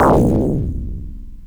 Index of /90_sSampleCDs/AKAI S6000 CD-ROM - Volume 1/SOUND_EFFECT/EXPLOSIONS
EXPLOMIX0.WAV